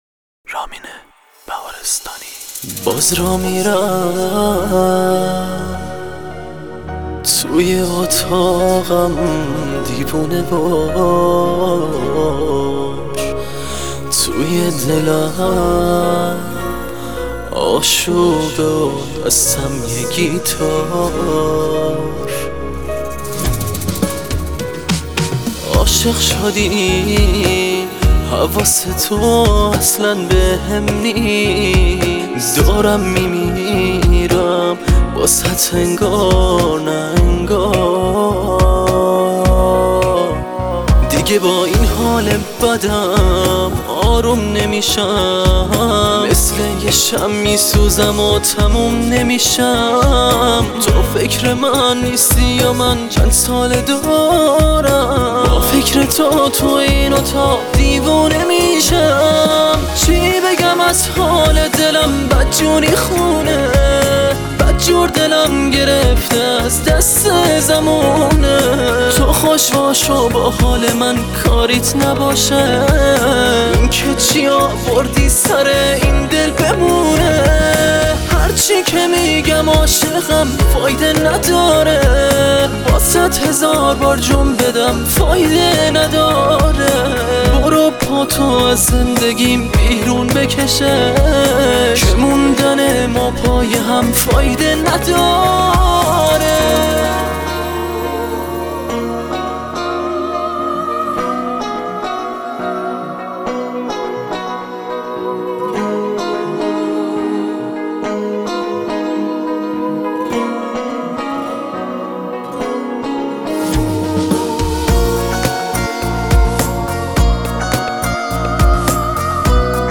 فوق العاده غمگین و احساسی